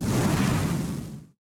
foundry-fire-whoosh-2.ogg